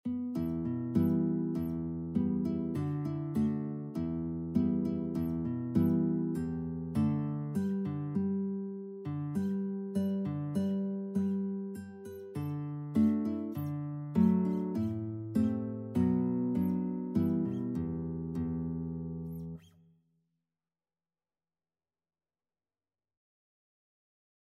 Free Sheet music for Guitar
Traditional Music of unknown author.
E minor (Sounding Pitch) (View more E minor Music for Guitar )
2/4 (View more 2/4 Music)
Moderato
Guitar  (View more Easy Guitar Music)
Traditional (View more Traditional Guitar Music)